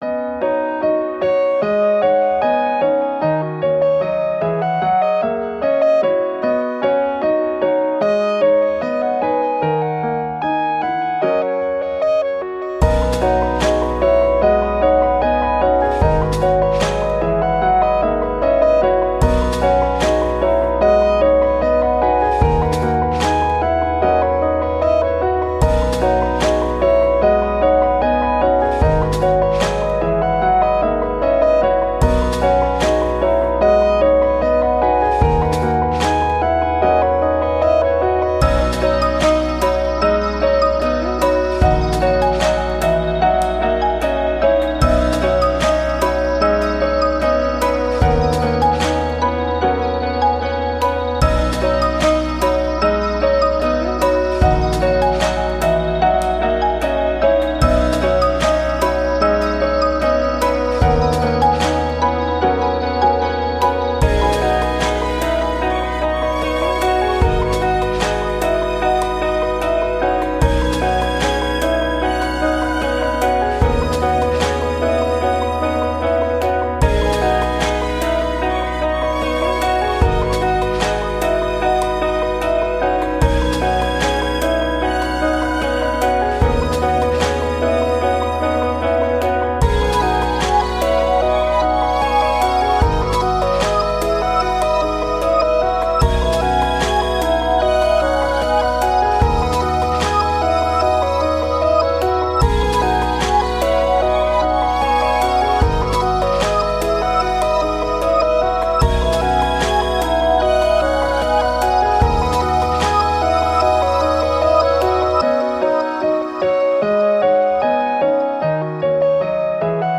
Ambient, Downtempo, Soundtrack, Hopeful